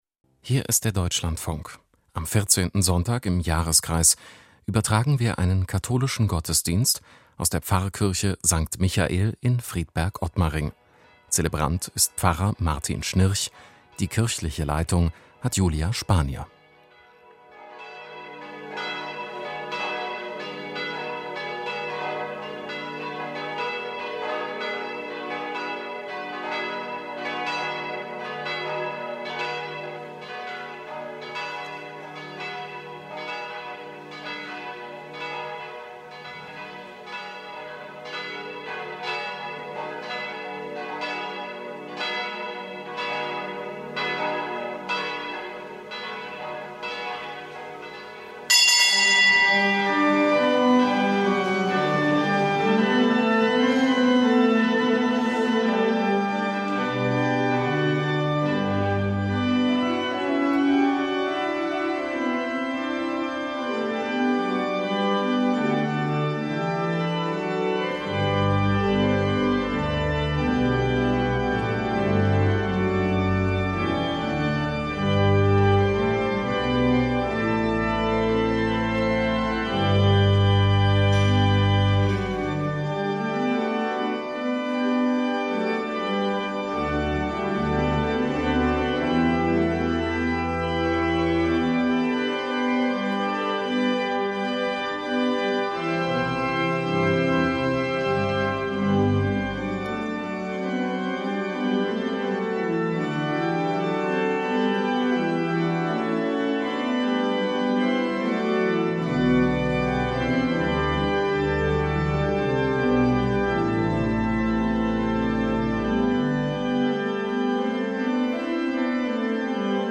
Kirchensendung - Gottesdienst